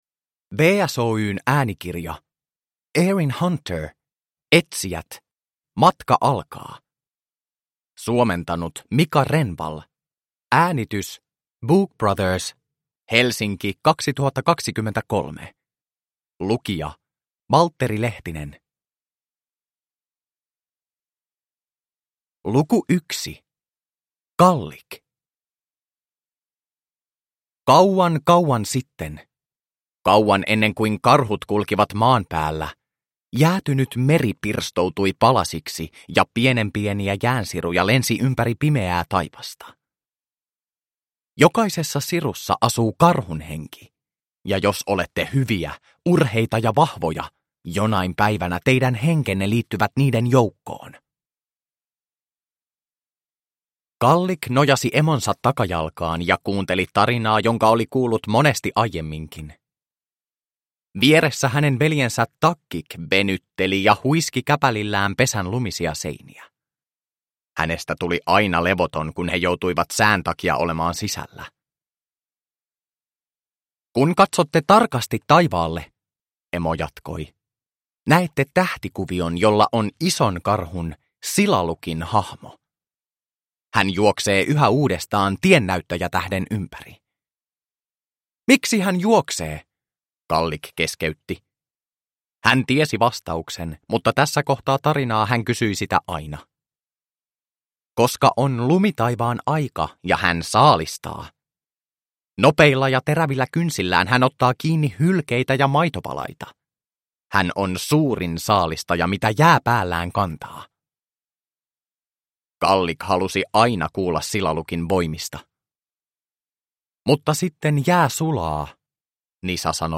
Etsijät: Matka alkaa – Ljudbok – Laddas ner